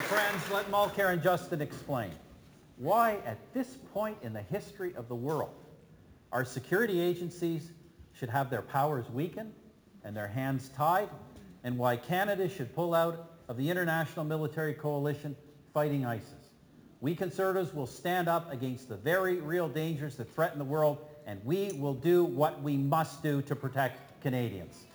That from Stephen Harper at the Coastal Black Winery Thursday night for his press conference in front of many voters.